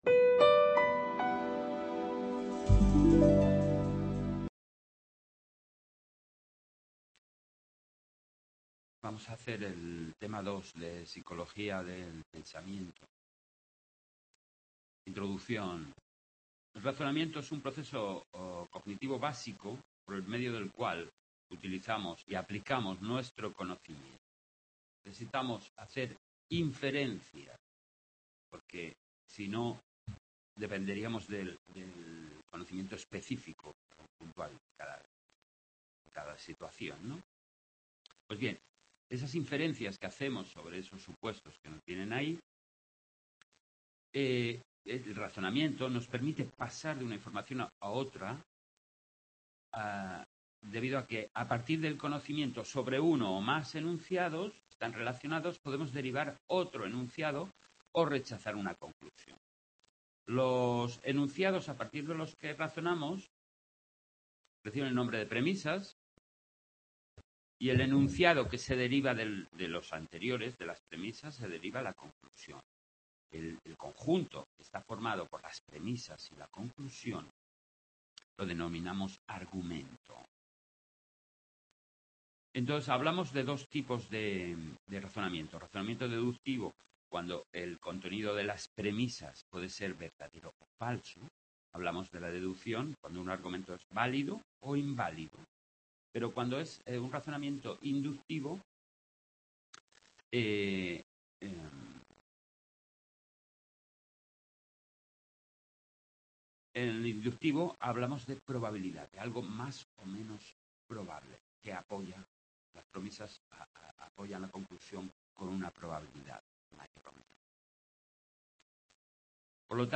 2-psic. Pensamiento-2021- Description Tema 2 de Psic. del Pensamiento. Grabado en el aula de Sant Boi